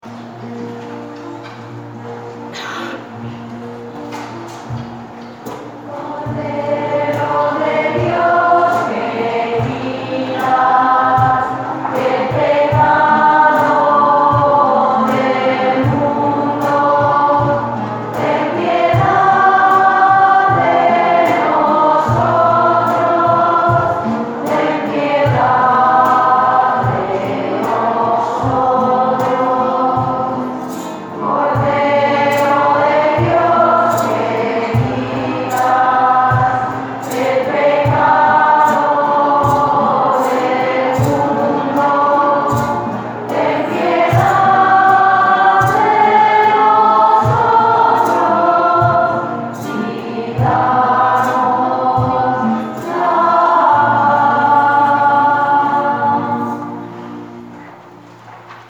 CORDERO-DE-DIOS-lento-II.mp3